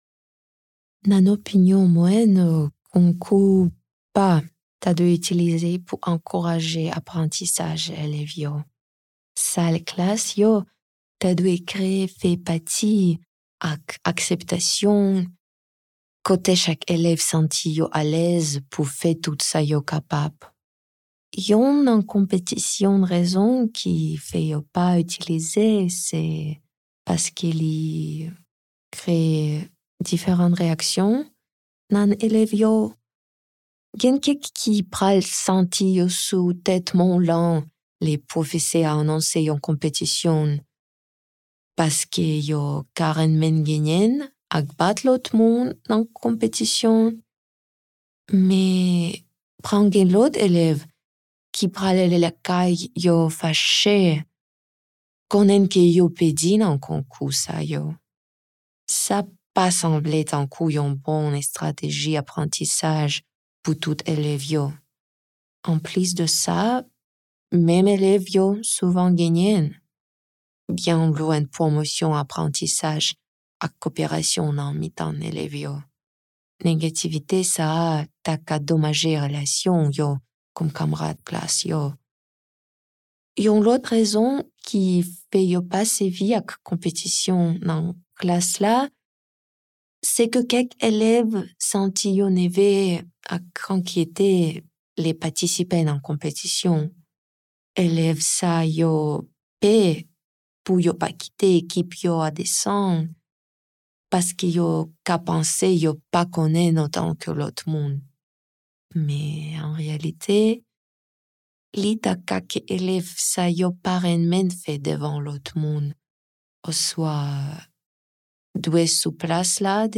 Presentational Speaking: Haitian Creole
[Note: In the transcript below, ellipses indicate that the speaker paused.]